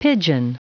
Prononciation du mot pigeon en anglais (fichier audio)
Prononciation du mot : pigeon